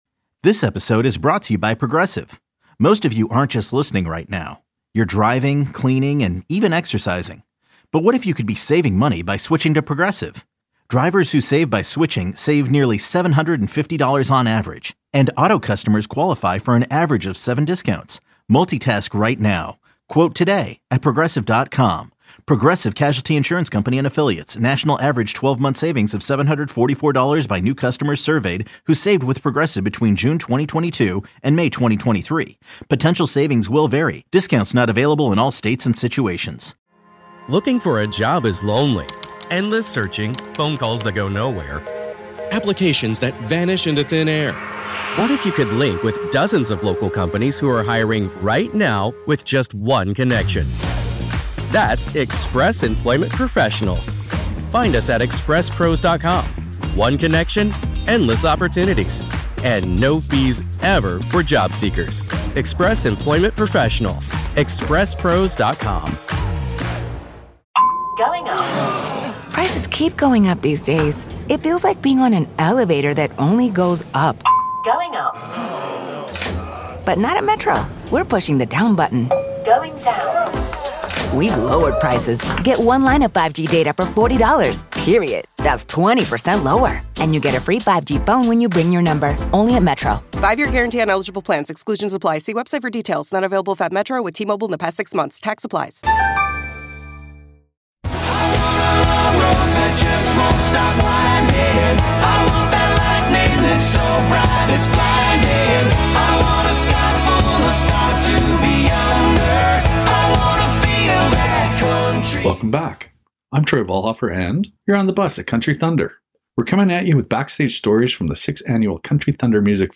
interviews the top country music artists on his tour bus.